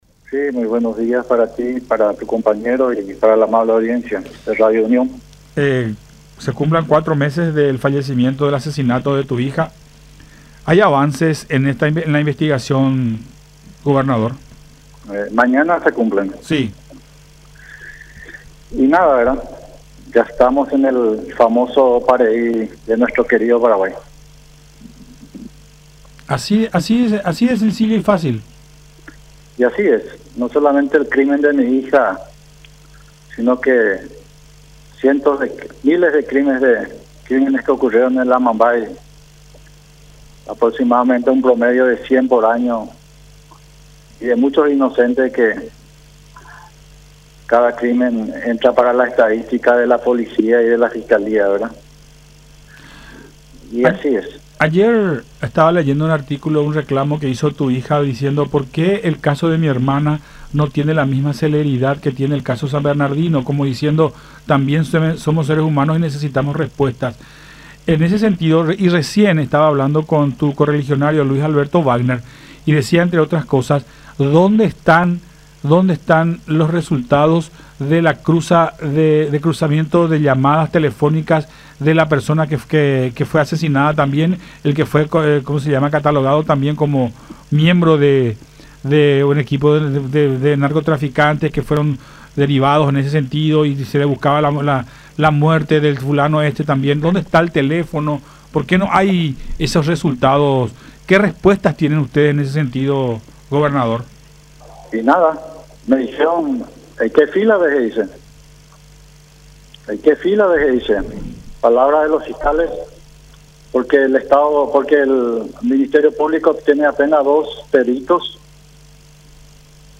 en charla con Todas Las Voces por La Unión